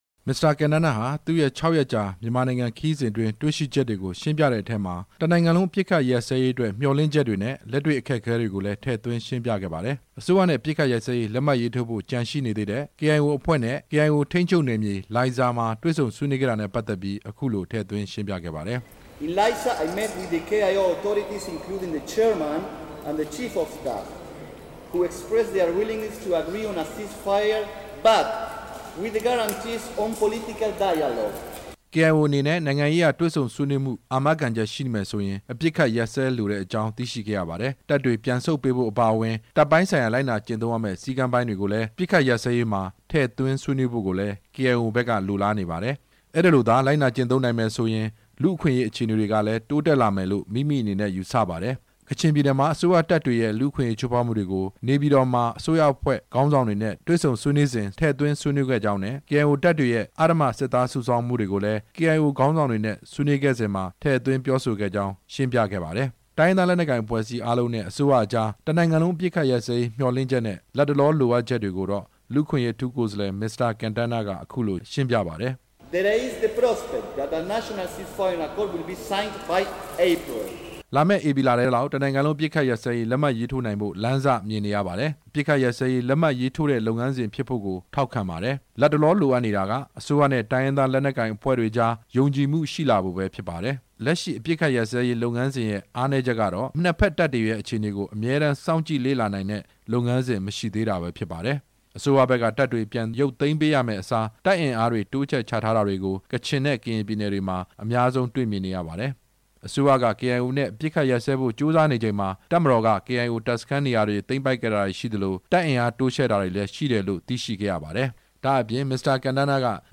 ရခိုင်ပြည်နယ်အတွင်း မကြာခင်က ဖြစ်ပွားခဲ့တဲ့ အကြမ်းဖက် ပဋိပက္ခတွေနဲ့ ပတ်သက်ပြီး အစိုးရ စုံစမ်းရေးကော်မရှင်ရဲ့ စုံစမ်းမှုတွေဟာ လူသေဆုံး ခဲ့တာတွေ၊ လုယက်မီးရှို့ခံရတာတွေ၊ အဓမ္မကျင့်ကြံ ခံရတာတွေအပေါ် အခုထိတော့ ကျေနပ် စရာကောင်းအောင် မရှင်းလင်းနိုင်သေးဘူးလို့ မစ္စတာ ကင်တားနားက ပြောပါတယ်။ ၆ ရက်ကြာ မြန်မာပြည် ခရီးစဉ်အပြီး ရန်ကုန်လေဆိပ်မှာ ကျင်းပခဲ့တဲ့ သတင်းစာရှင်းလင်းပွဲမှာ ပြောကြားခဲ့တာပါ။